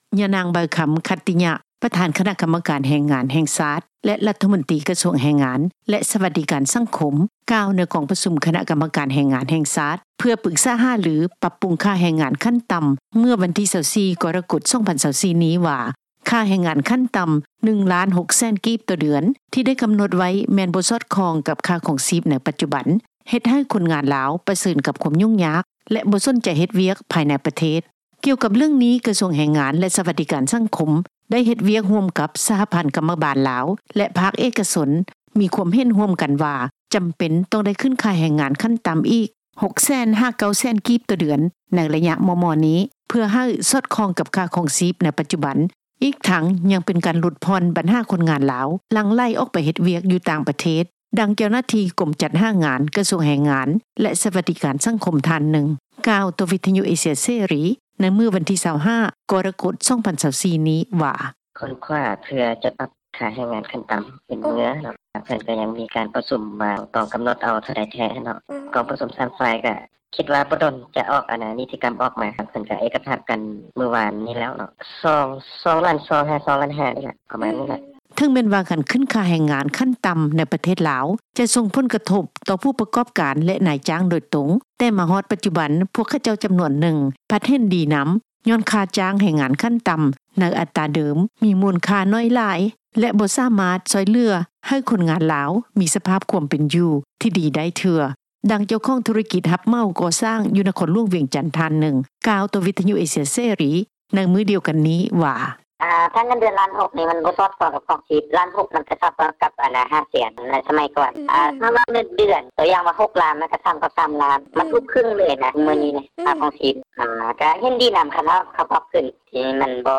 ດັ່ງ ຄົນງານລາວ ທີ່ກໍາລັງຊອກວຽກເຮັດ ຢູ່ນະຄອນຫລວງວຽງຈັນ ທ່ານໜຶ່ງ ກ່າວວ່າ:
ດັ່ງ ຄົນງານລາວ ທີ່ກໍາລັງຊອກເຮັດວຽກ ຢູ່ນະຄອນຫລວງວຽງຈັນ ອີກທ່ານໜຶ່ງ ກ່າວວ່າ: